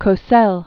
(kō-sĕl), Howard Originally Howard William Cohen. 1918-1995.